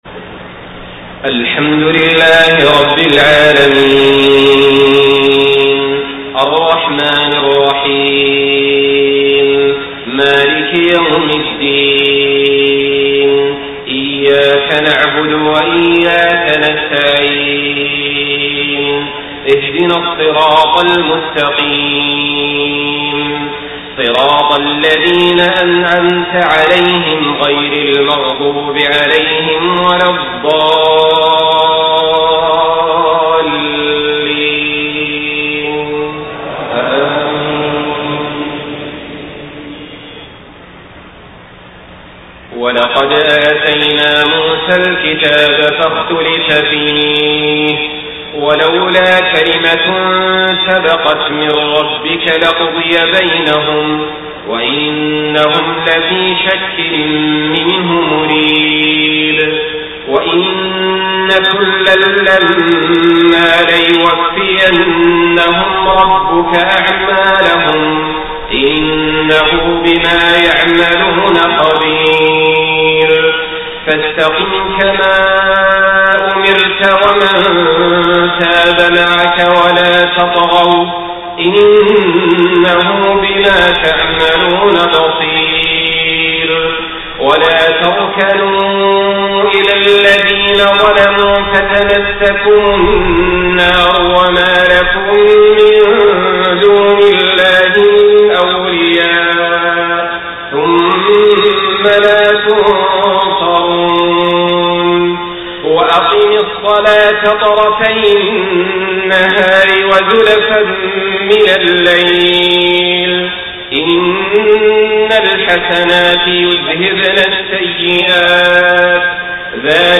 صلاة العشاء 4 ربيع الأول 1431هـ خواتيم سورة هود 110-123 > 1431 🕋 > الفروض - تلاوات الحرمين